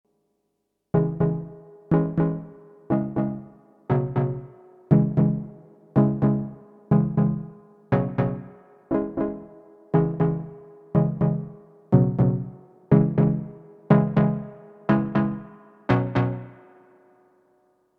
A large part of the sound is in the delay and reverb.
I’ve just messing around with my Prophet 10 and Volante and the results are very close to “Dem Never Know”.
The secret seems to be using poly mod from the filter env.
Both oscillators set to saw tooth and tuned to C1, OSC B is slightly detuned.
Finally a slow bit of LFO going to the filter, source mix set slightly more to noise.